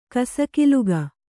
♪ kasakiluga